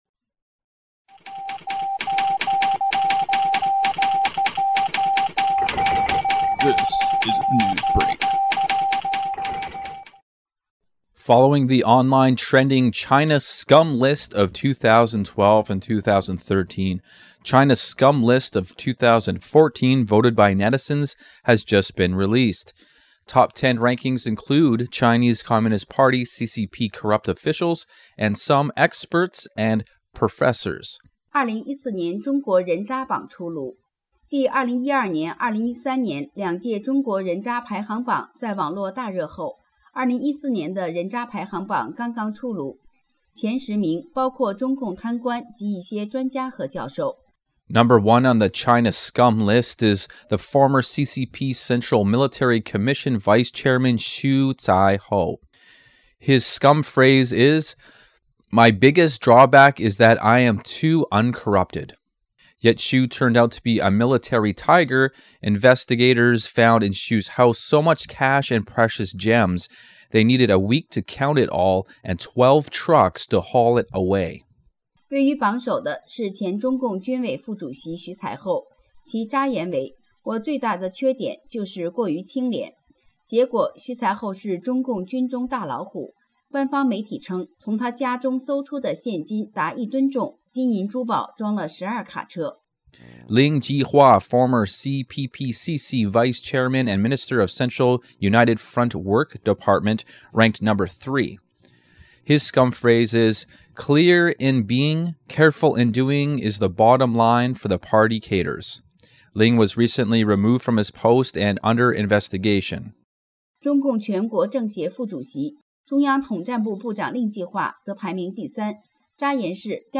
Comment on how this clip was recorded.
128kbps Mono